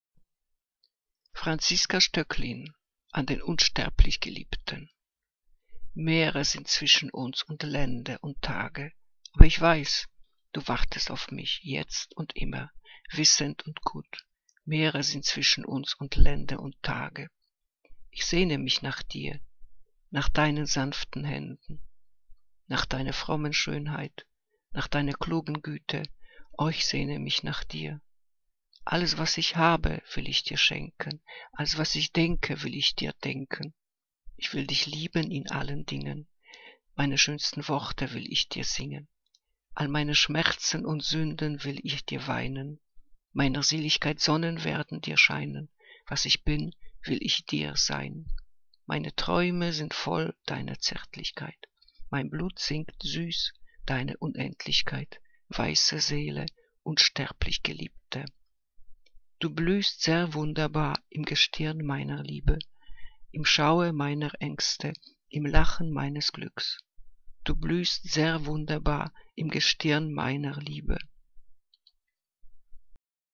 Liebeslyrik deutscher Dichter und Dichterinnen - gesprochen (Francisca Stoecklin)